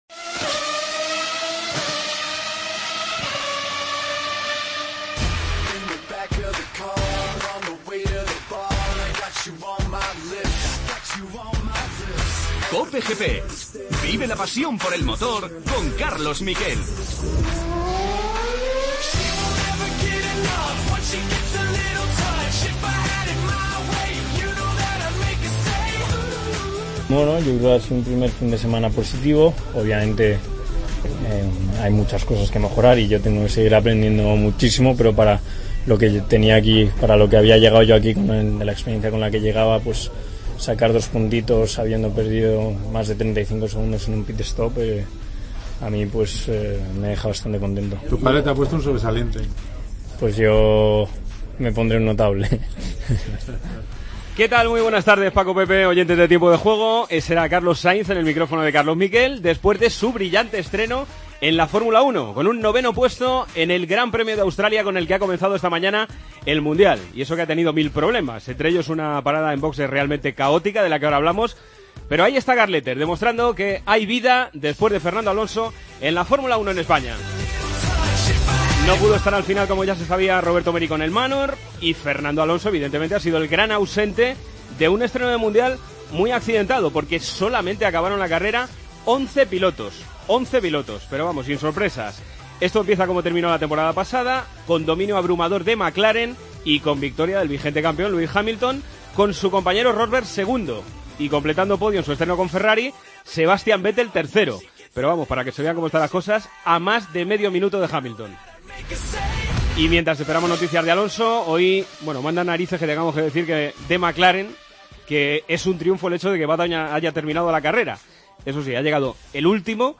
Tertulia.